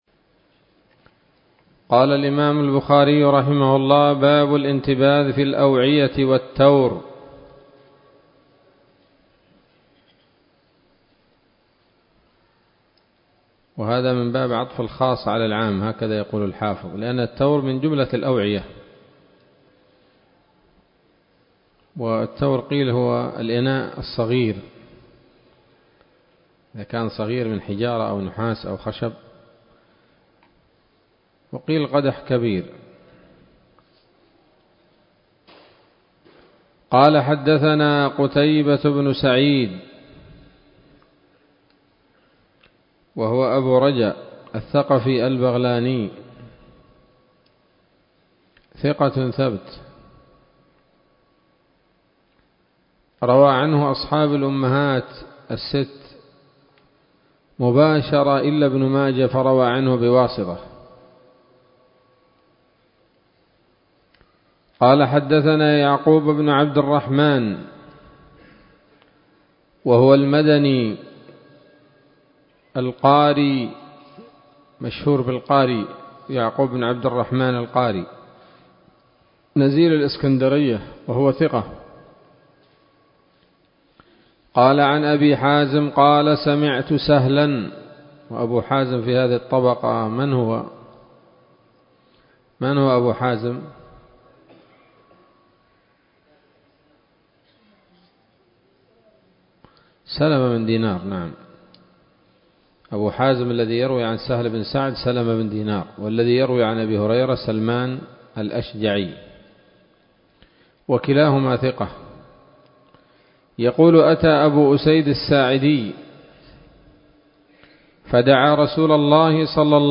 الدرس السابع من كتاب الأشربة من صحيح الإمام البخاري